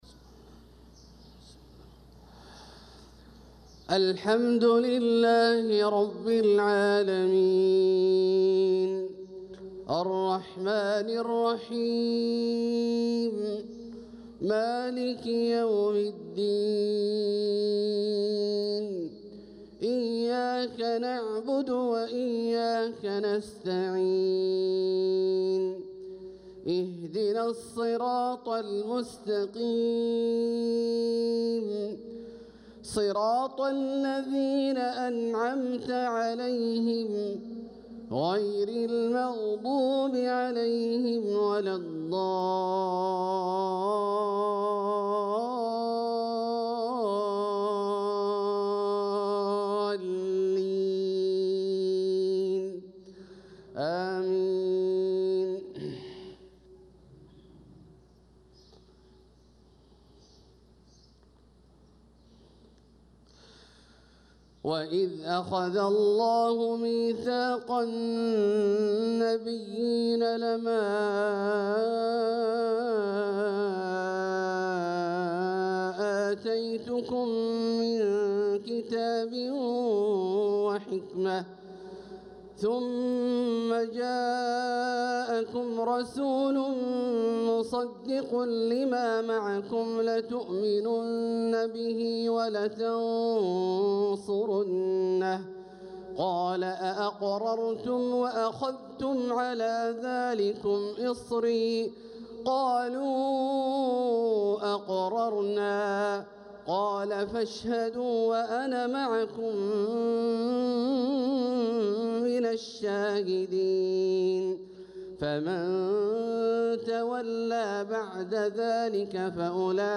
صلاة الفجر للقارئ عبدالله الجهني 9 صفر 1446 هـ
تِلَاوَات الْحَرَمَيْن .